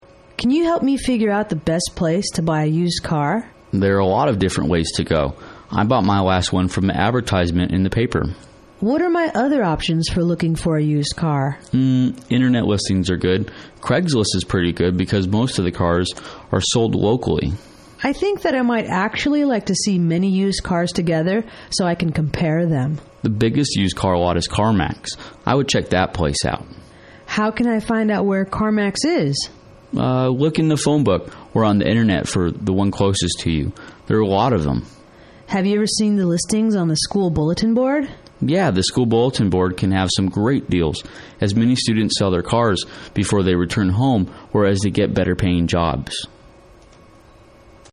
英语情景对话-Where to Buy a Used Car(2) 听力文件下载—在线英语听力室